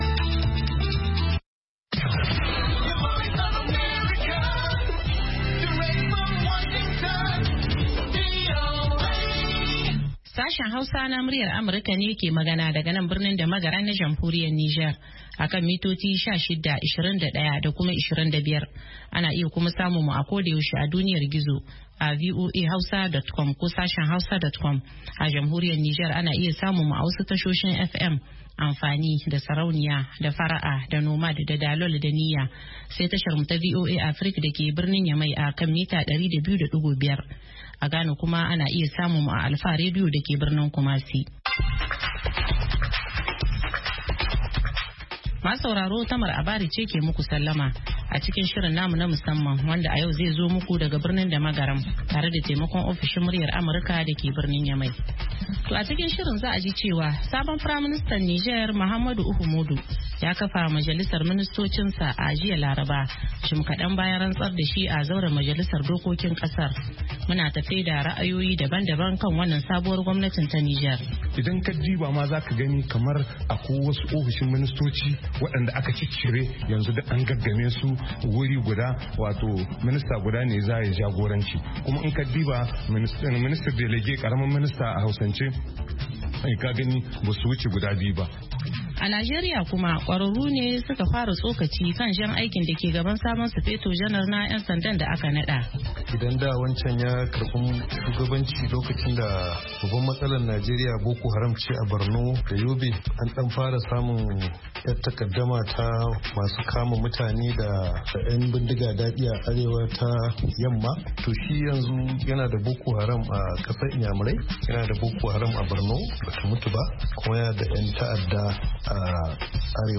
Mu kan komo da karfe 8 na safe agogon Najeriya da Nijar domin sake gabatar muku da labarai da hirarraki, da sharhin jaridu kama daga Najeriya zuwa Nijar har Ghana, da kuma ra’ayoyinku.